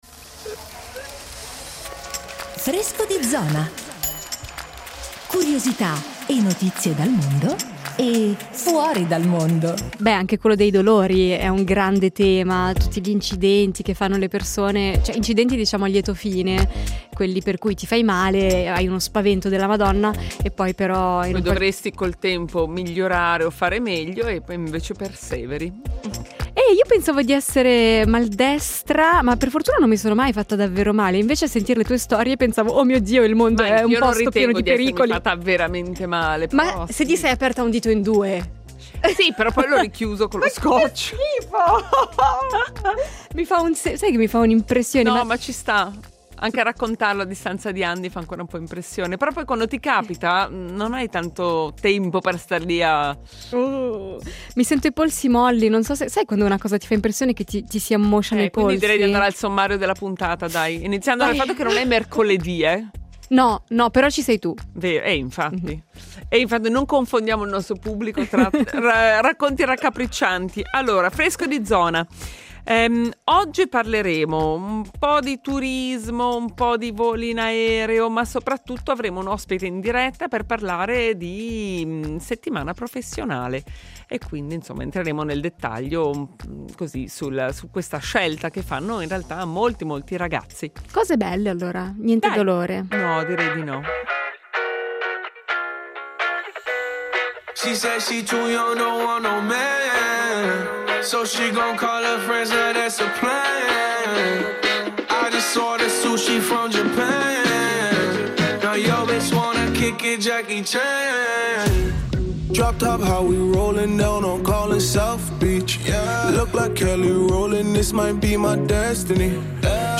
Settimana formazione professionale (ospite in diretta)